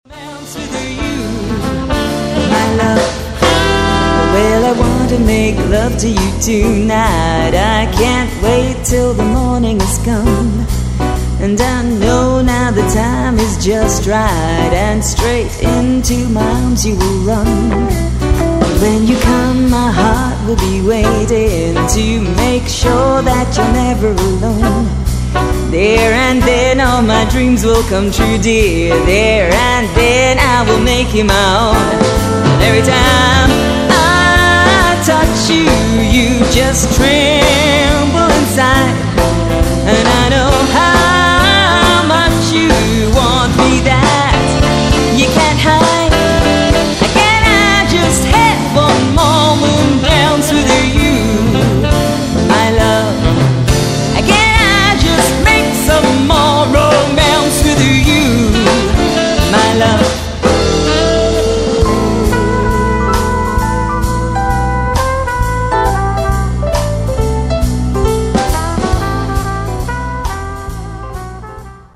Wedding band soundclips